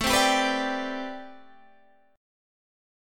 Am11 Chord (page 2)
Listen to Am11 strummed